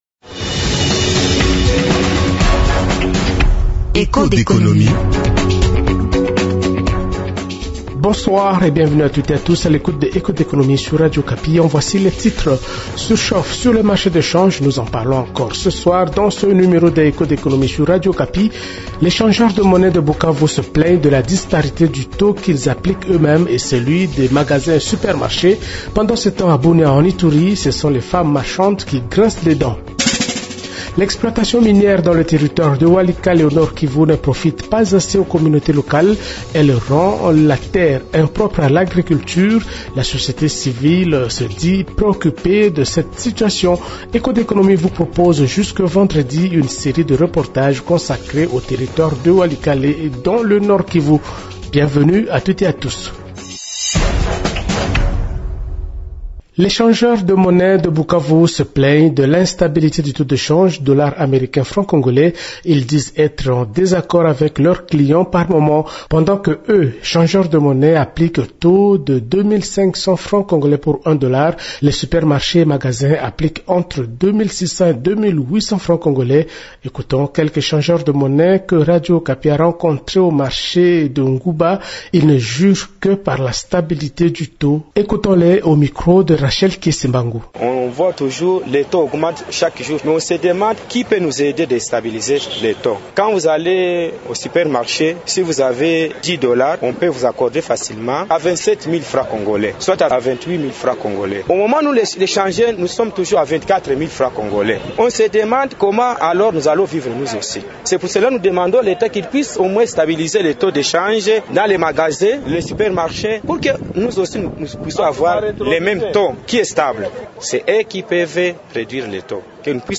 Pendant que eux, changeurs de monnaie appliquent le taux de 2500 francs pour 1 dollar, les supermarchés et magasins appliquent entre 2.600 et 2.800 FC. Ecoutons quelques changeurs de monnaie que radio Okapi a rencontrés au marché de Nguba dans la commune d'Ibanda, ils ne jurent que par la stabilité du taux.